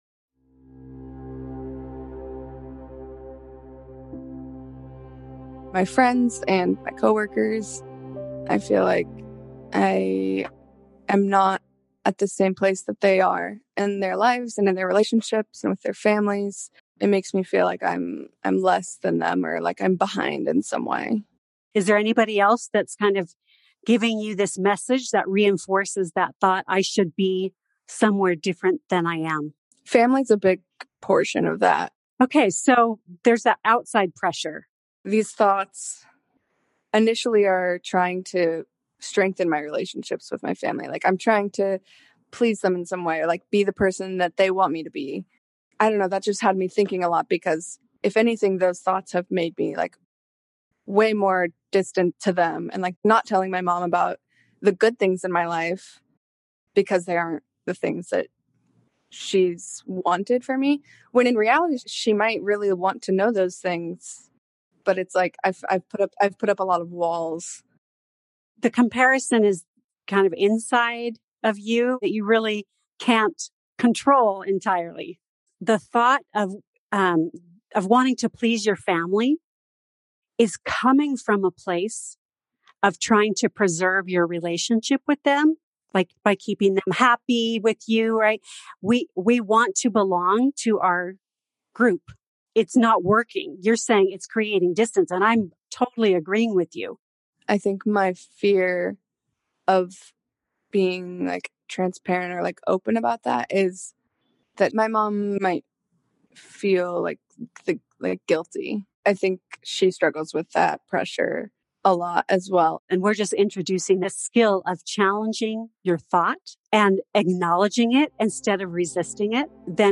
Meomind provides unlimited access to a library of 1000+ pre-recorded therapy sessions, featuring a handpicked team of licensed providers.
White female student in her mid-20’s